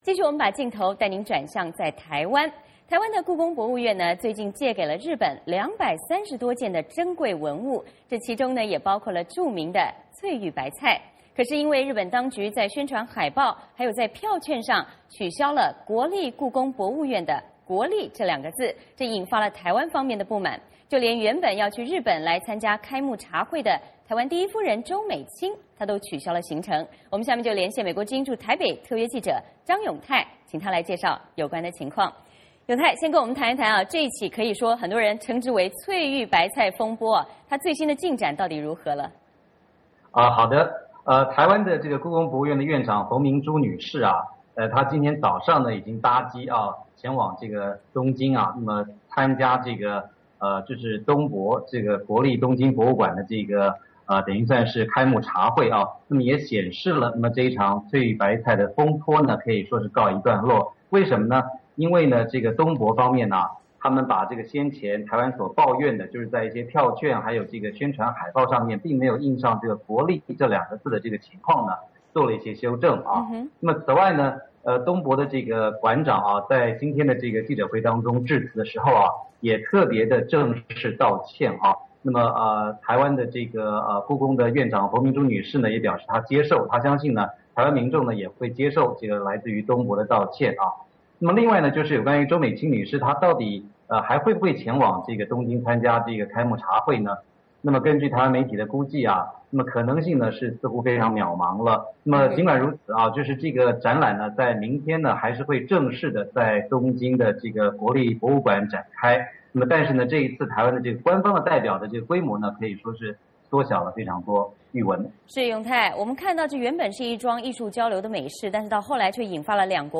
VOA连线：翠玉白菜争议，台日关系蒙阴影